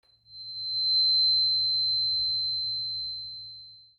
Handcrafted 4096Hz Crystal Tuning Fork made from high-grade aluminium, providing a long and enduring note.
4096Hz Aluminium Unweighted Tuning Fork for activating crystals.
The handcrafted tuning fork is electronically tuned at 20°C and is made from high-grade aluminium, providing a long and enduring note.
4096Hz-Tuning-Fork.mp3